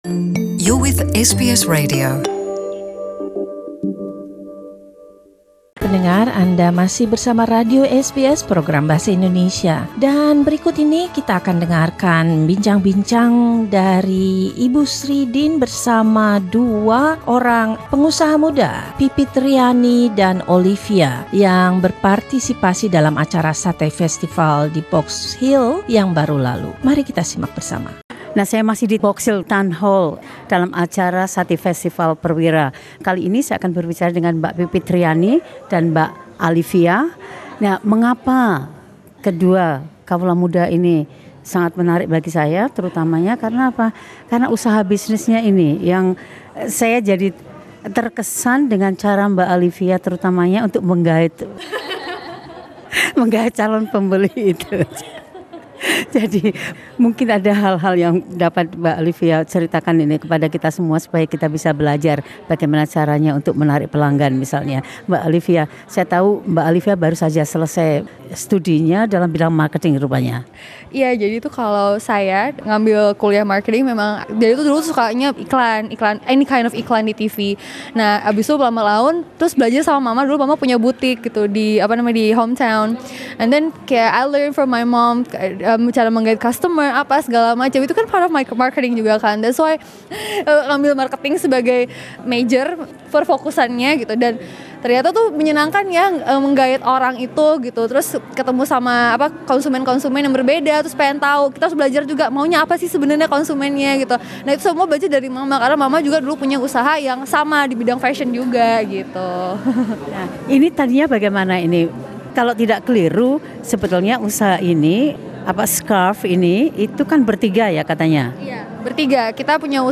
Pada Festival Sate 2018 yang diselenggarakan oleh PERWIRA